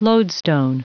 Prononciation du mot lodestone en anglais (fichier audio)
Prononciation du mot : lodestone